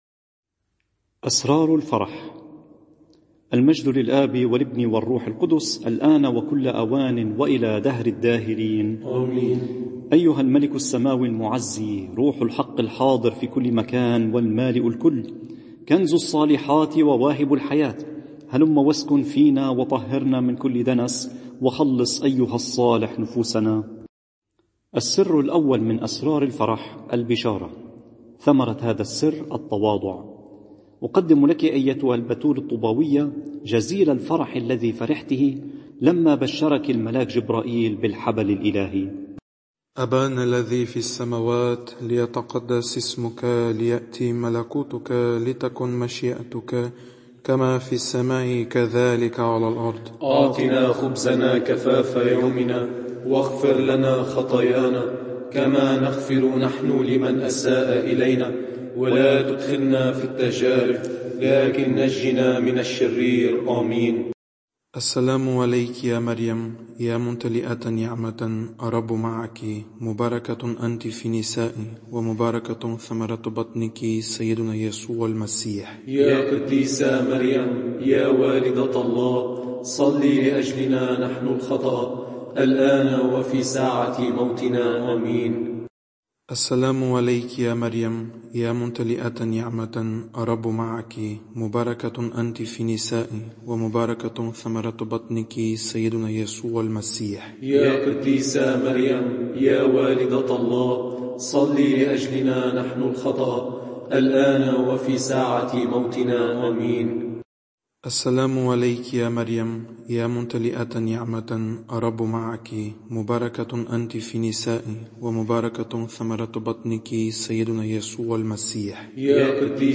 نور ونار لشرح الإنجيل المقدّس، عظات، مواضيع وأحاديث روحيّة، عقائديّة ورهبانيّة…